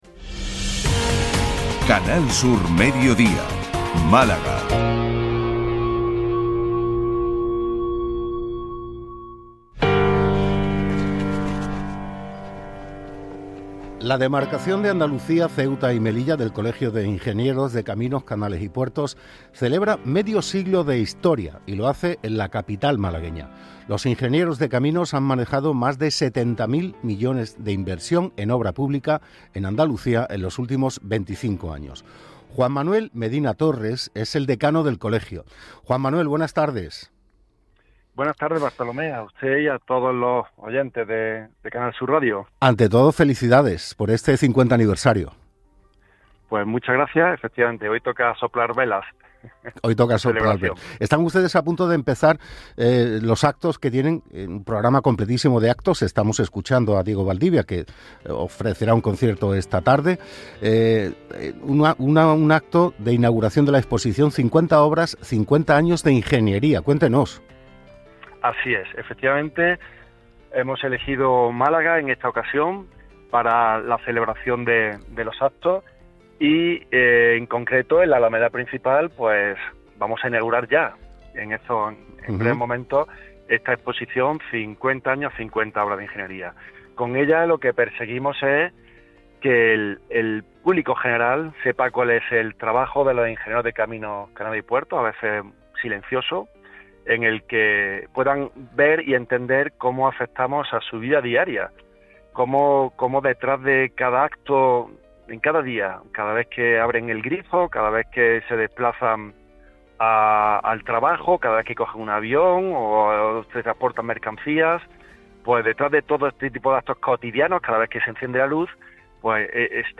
ENTREVISTA EN CANAL SUR RADIO. 50 ANIVERSARIO